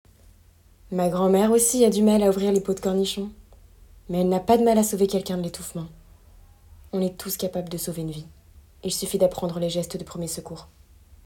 - Mezzo-soprano